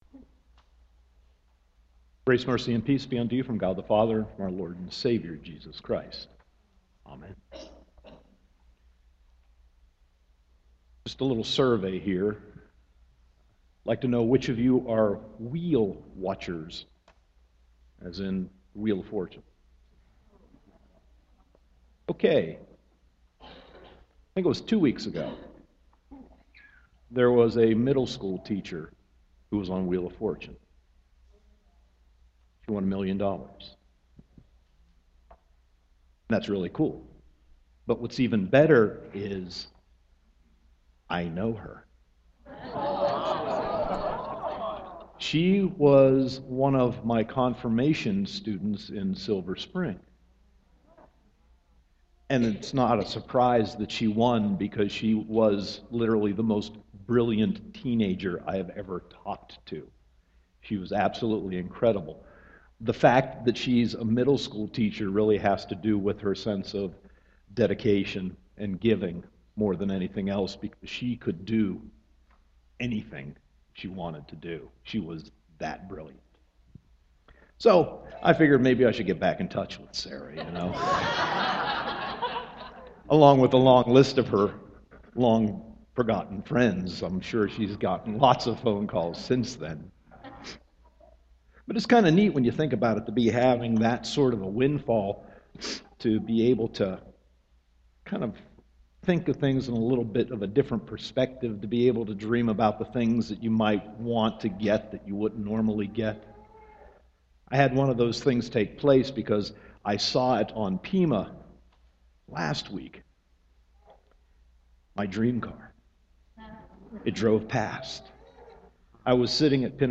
Sermon 9.28.2014 -